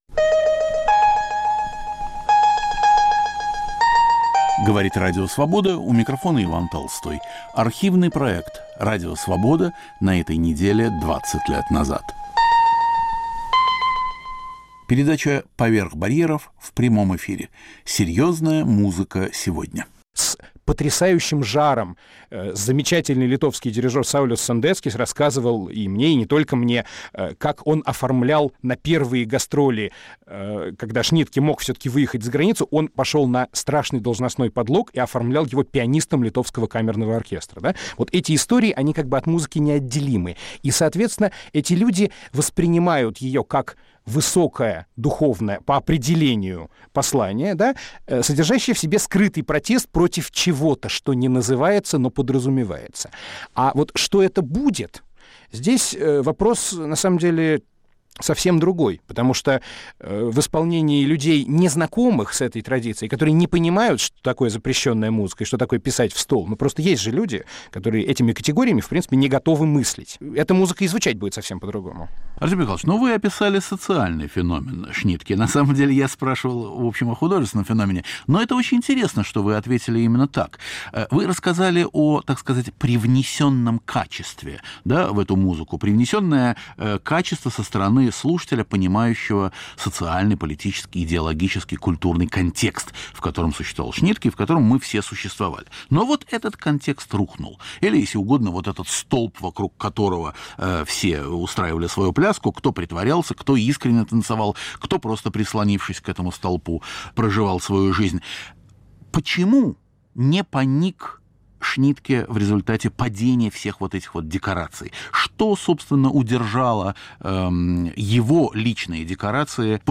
К 70-летию со дня рождения композитора Альфреда Шнитке. В передаче звучит Третий концерт для скрипки и камерного оркестра, 1978.